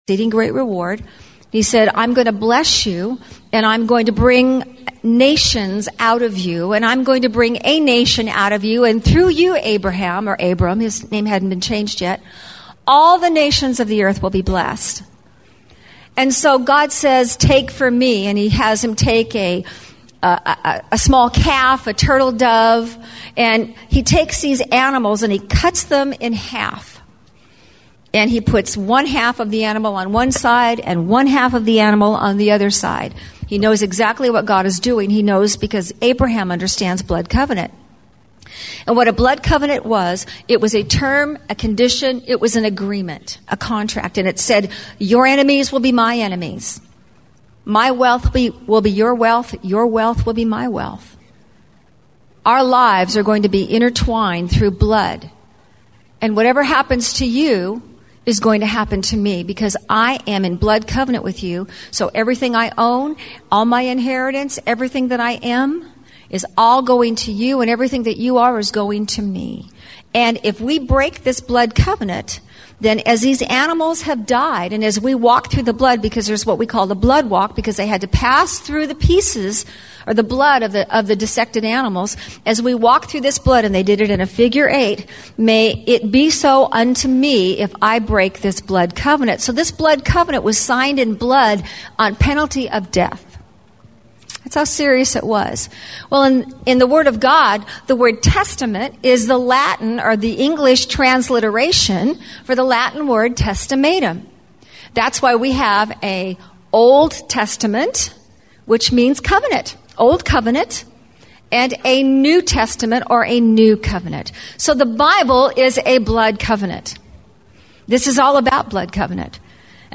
Welcome to The Rock Church's Archives. Here you can find all of our messages and blogs.